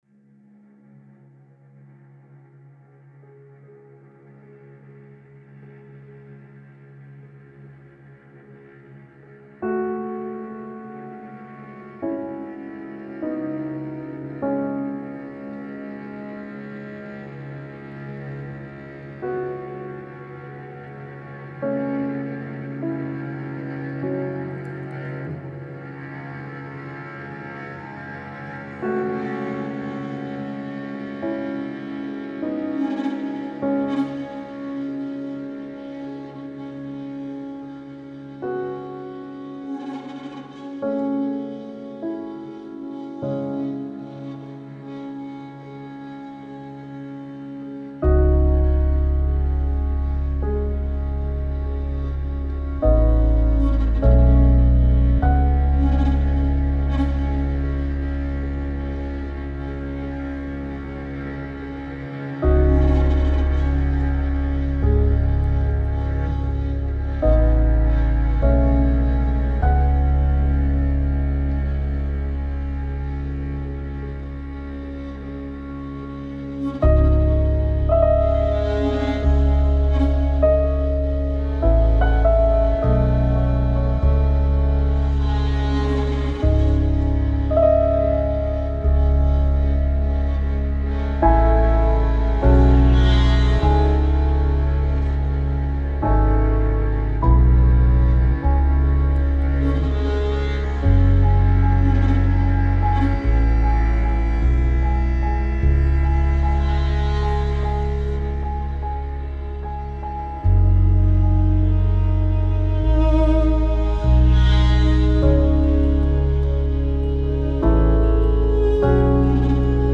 a modern classical / classical single